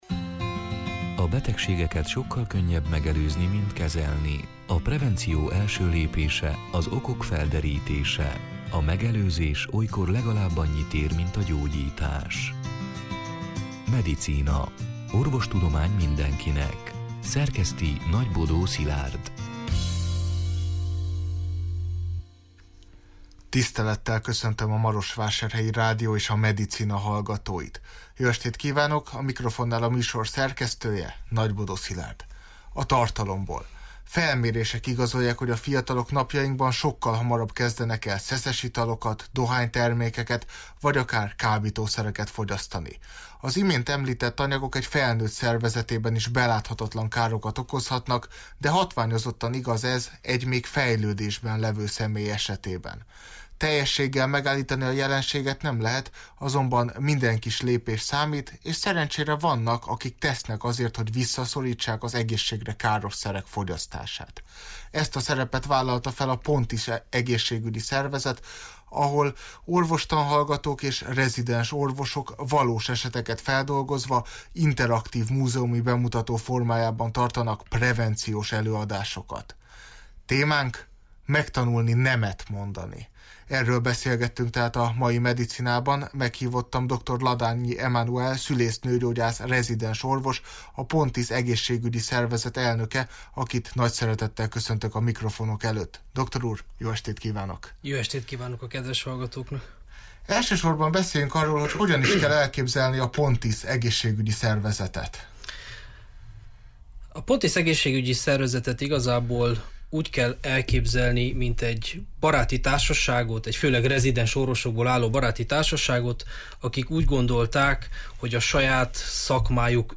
A Marosvásárhelyi Rádió (elhangzott: 2023. február elsején, szerdán este nyolc órától) Medicina c. műsorának hanganyaga: Felmérések igazolják, hogy a fiatalok napjainkban sokkal hamarabb kezdenek el szeszes italokat, dohánytermékeket vagy akár kábítószereket fogyasztani.